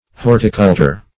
Search Result for " horticultor" : The Collaborative International Dictionary of English v.0.48: Horticultor \Hor"ti*cul`tor\, n. [NL., fr. L. hortus garden + cultor a cultivator, colere to cultivate.] One who cultivates a garden.